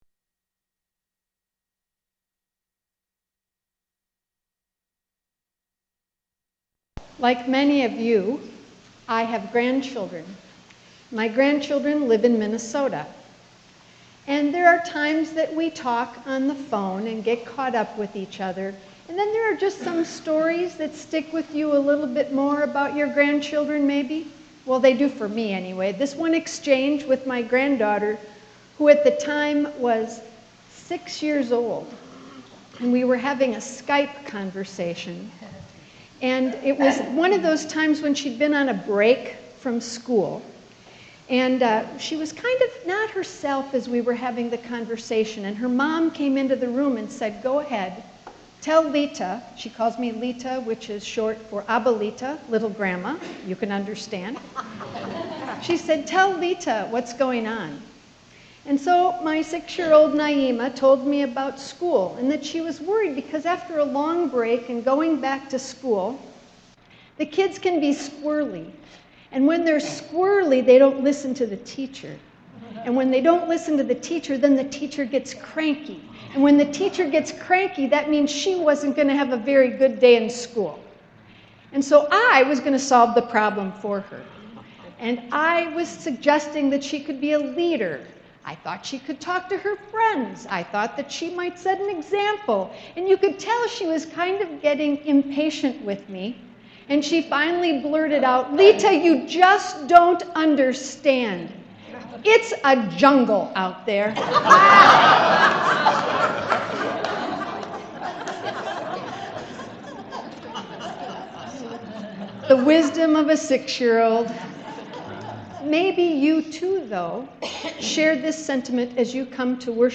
Sermon 11.22.2015